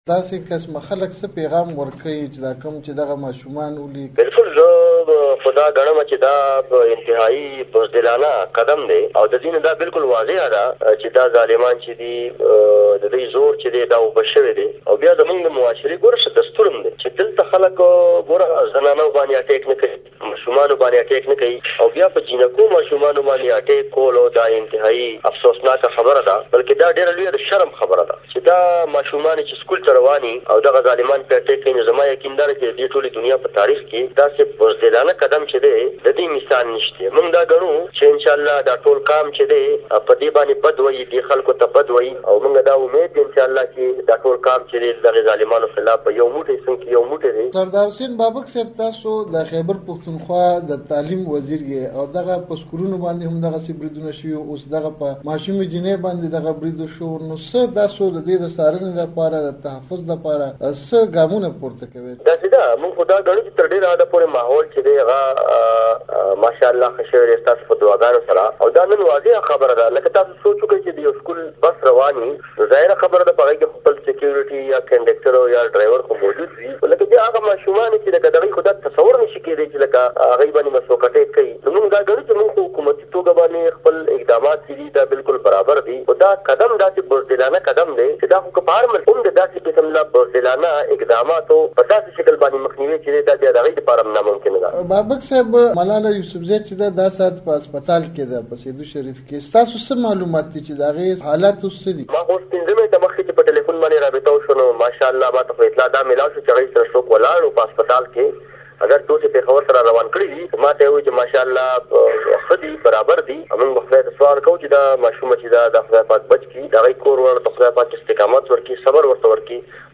د خیبر پښتونخوا د تعلیم وزیر سردار بابک له مشال راډيو سره په خبرو کې پر ملالې د وسله وال برید په کلکو ټکیو غندنه کړې